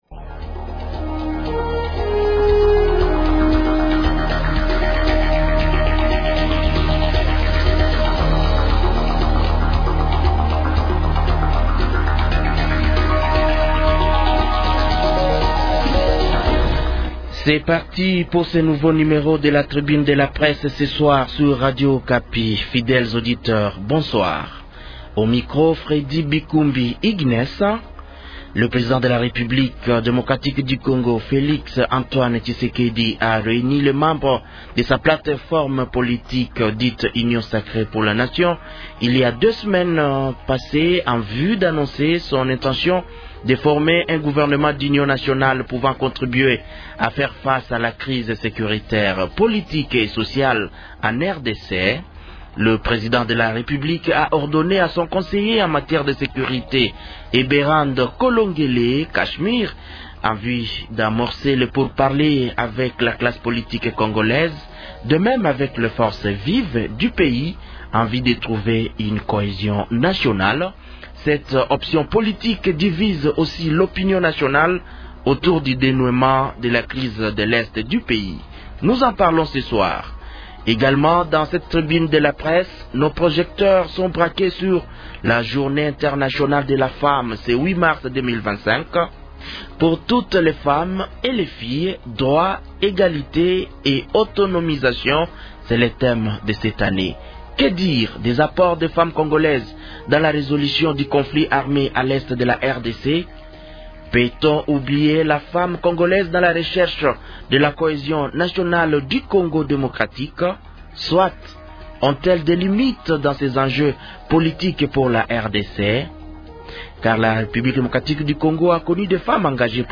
Tribune de la presse